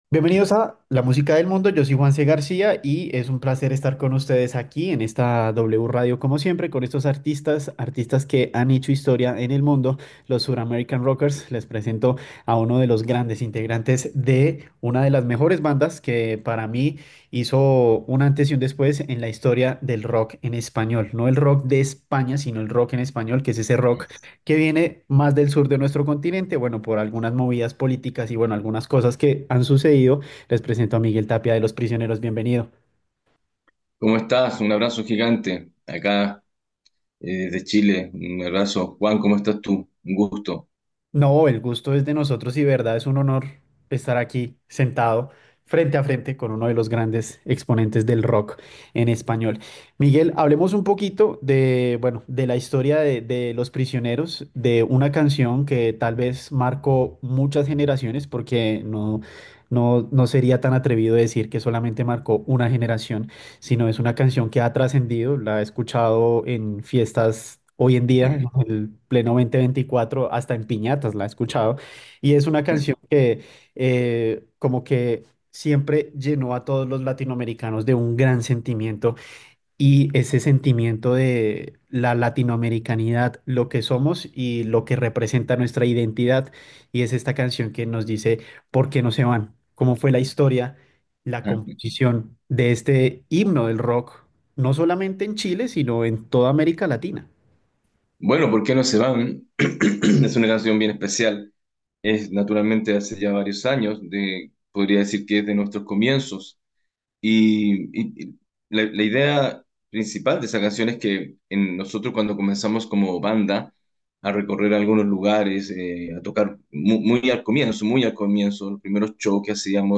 Miguel Tapia, vocalista y líder de la icónica banda chilena Los Prisioneros, revive la historia de una de las agrupaciones más influyentes del rock en español.